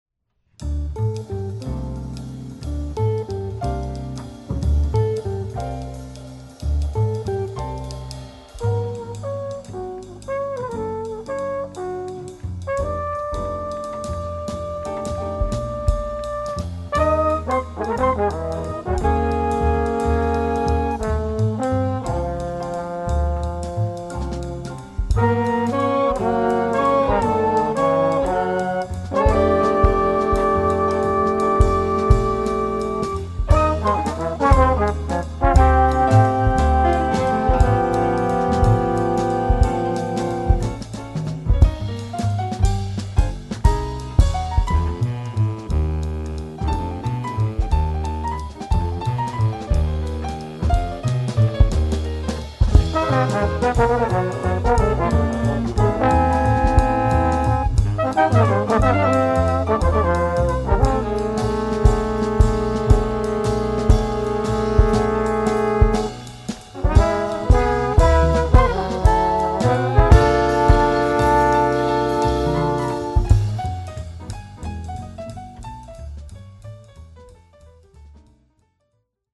Jazz Nonet: